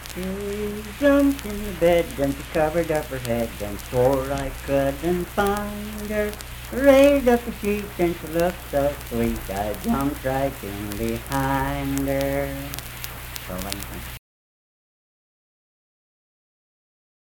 Unaccompanied vocal music performance
Bawdy Songs
Voice (sung)
Clay County (W. Va.)